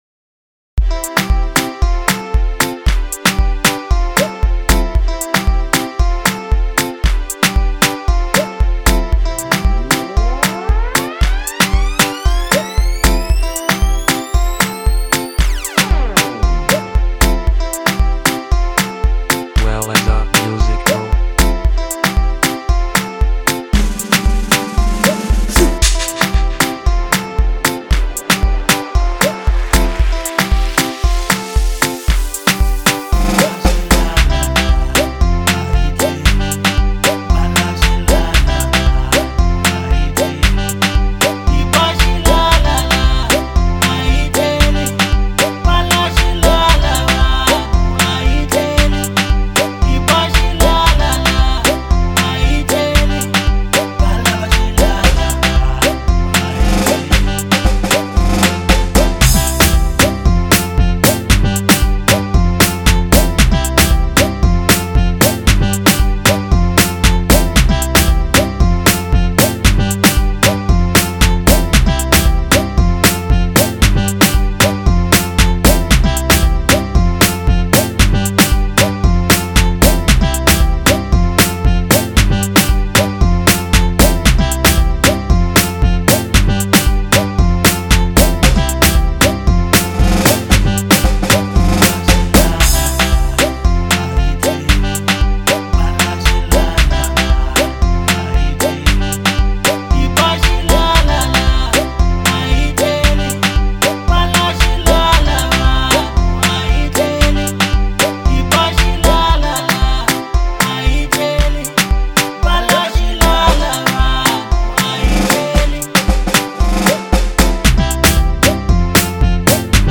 02:58 Genre : Local House Size